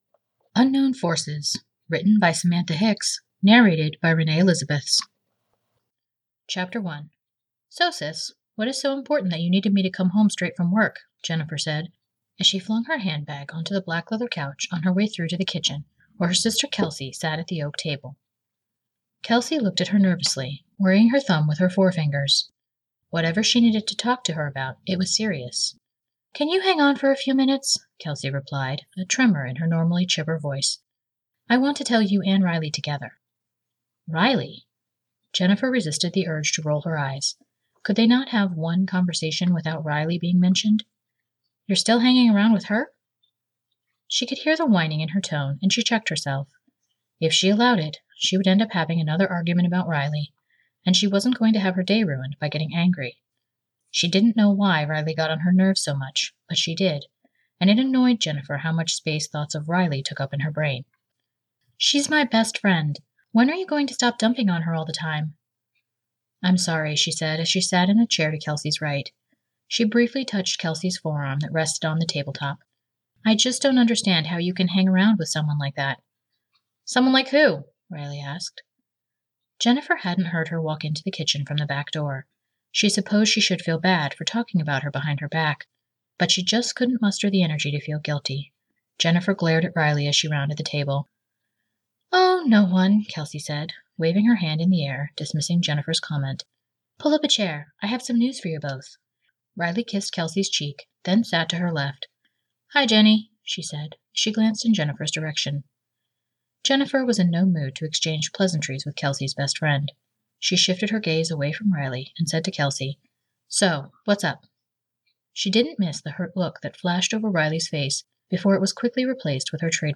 [Audiobook]